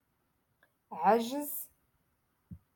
Moroccan Dialect- Rotation Six - Lesson TwoEleven